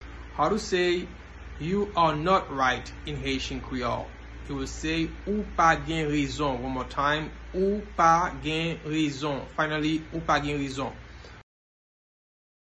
Pronunciation:
You-are-not-right-in-Haitian-Creole-Ou-pa-gen-rezon-pronunciation-by-a-Haitian-teacher.mp3